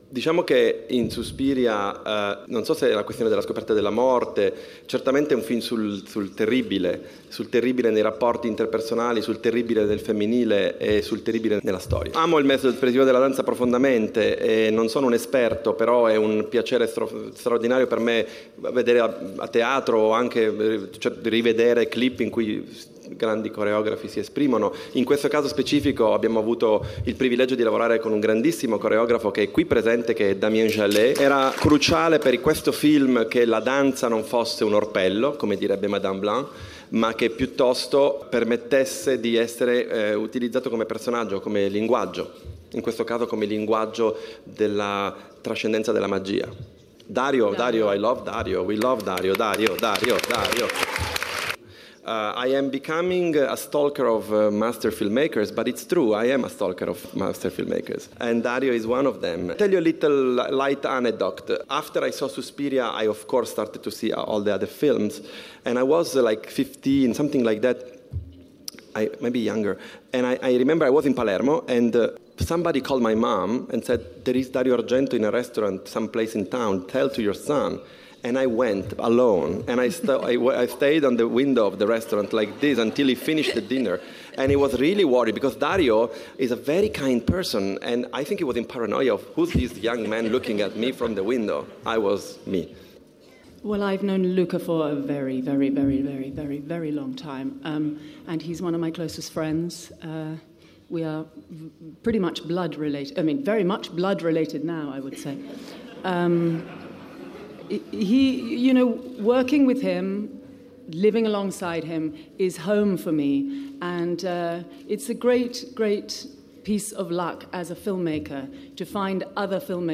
suspiria-luca-guadagnino-e-gli-attori-presentano-il-film.mp3